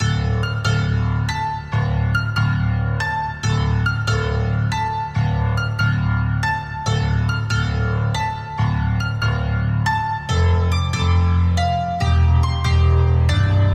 Cryosis Synth Bass 70 BPM
标签： 70 bpm Dancehall Loops Synth Loops 2.31 MB wav Key : Unknown
声道立体声